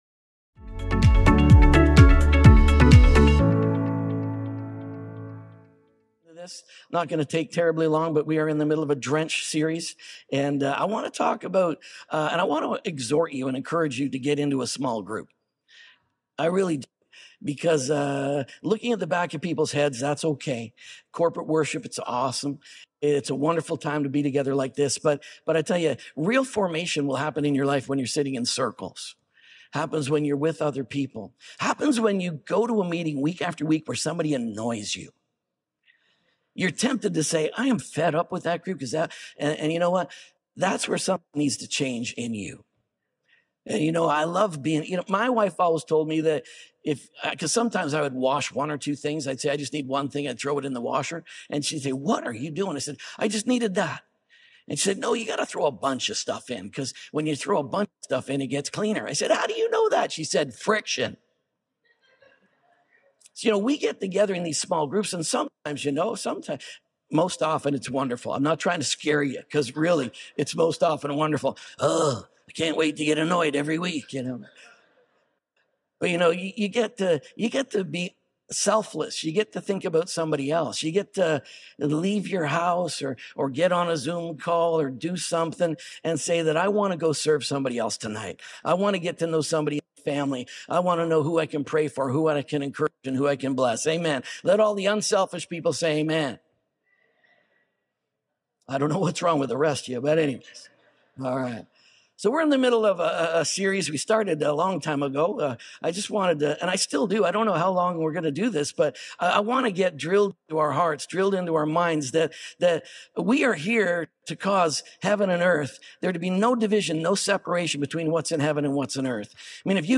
ONE | SERMON ONLY.mp3